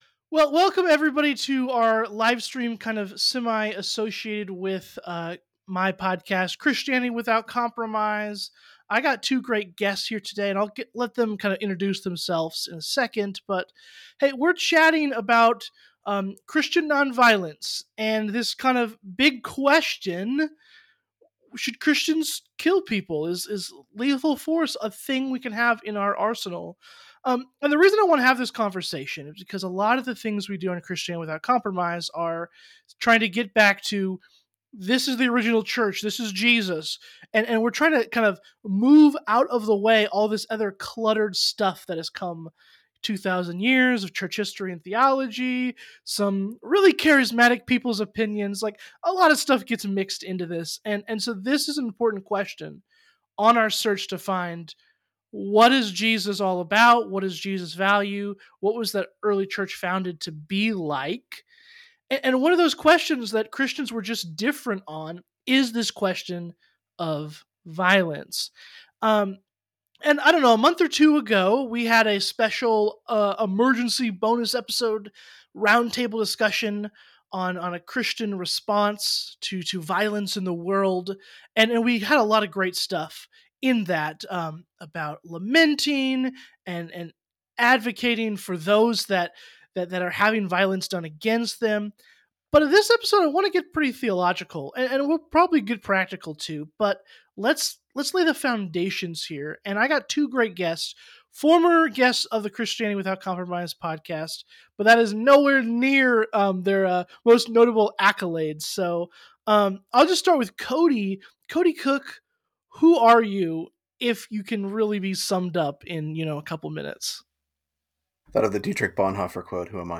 for a live roundtable on one provocative question: should Christians ever kill? They unpack what Jesus really taught about violence, how the early Church practiced enemy love, and why modern Christian ethics often distort that legacy.